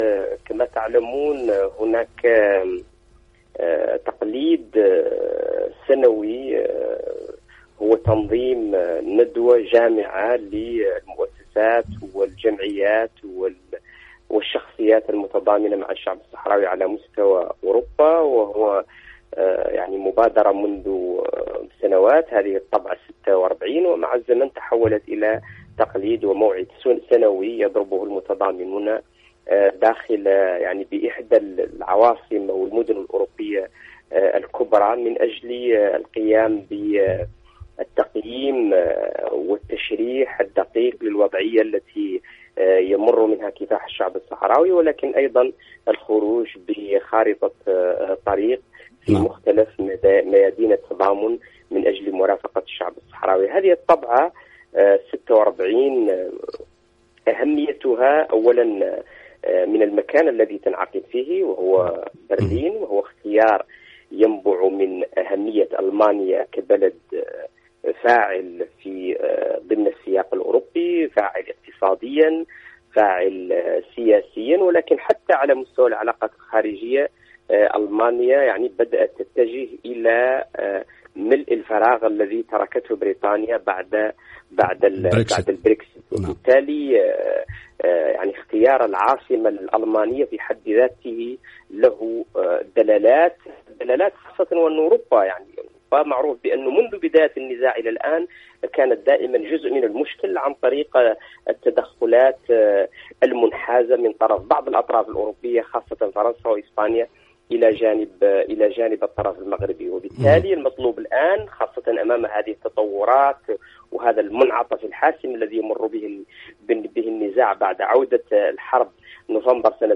تصريح أبي بشرايا البشير